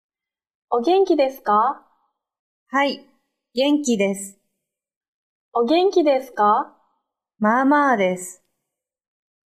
Nghe và nhắc lại đoạn hội thoại giữa Yamada và Hà.